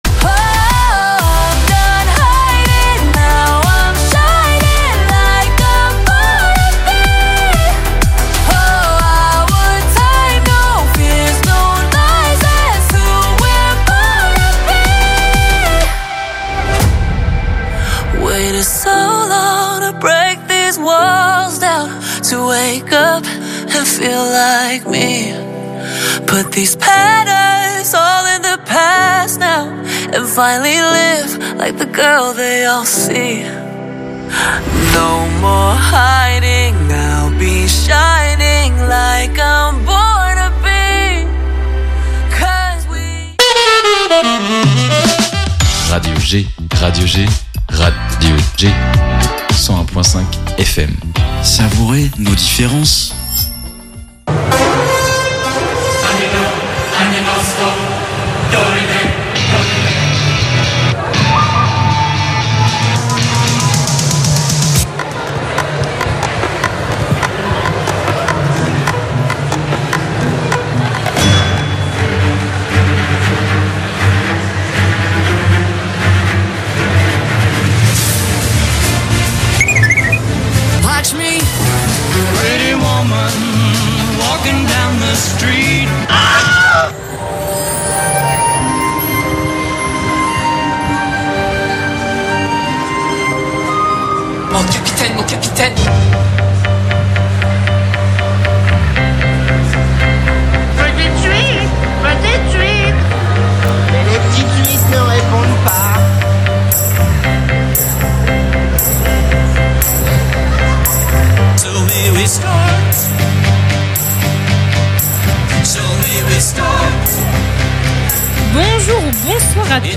Clap'N'Chat - Episode 15 - Spécial, 1h à l'Angers GeekFestOn se retrouve dans une nouvelle émission spéciale, cette fois, en plein coeur de l'Angers GeekFes